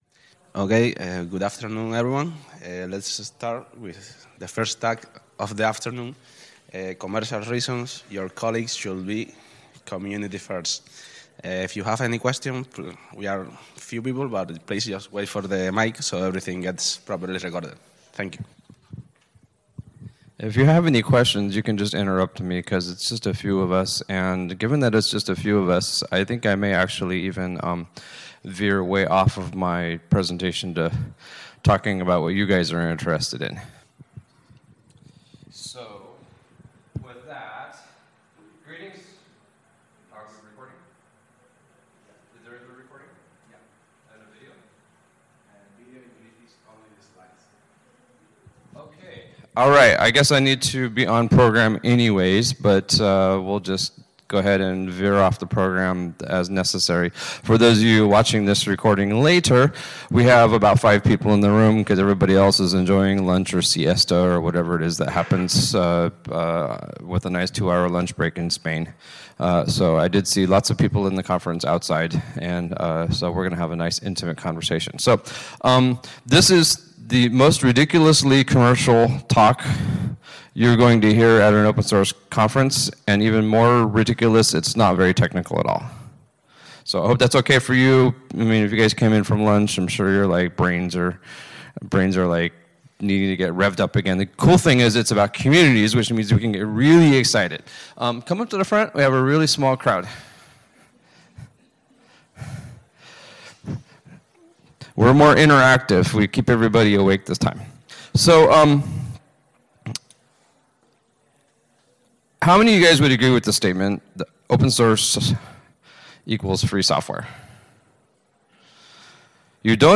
ApacheCon Seville 2016